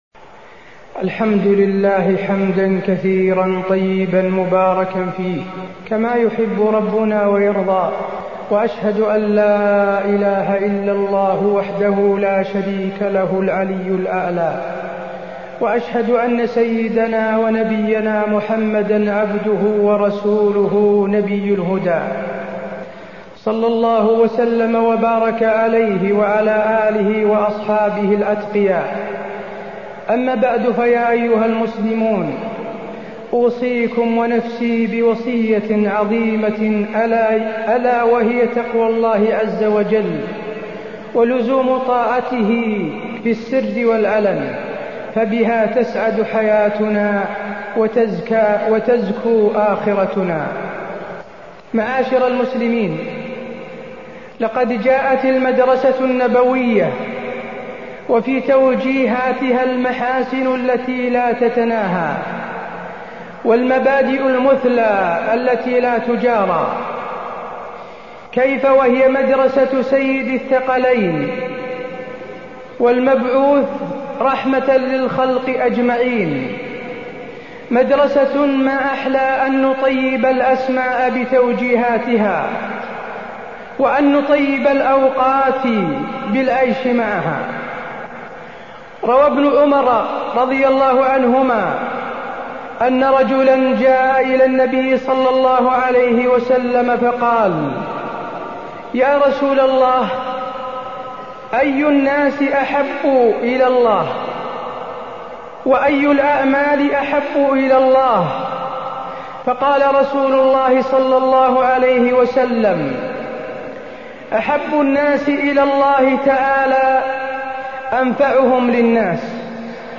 تاريخ النشر ١٠ شعبان ١٤٢٥ هـ المكان: المسجد النبوي الشيخ: فضيلة الشيخ د. حسين بن عبدالعزيز آل الشيخ فضيلة الشيخ د. حسين بن عبدالعزيز آل الشيخ أحب الأعمال الى الله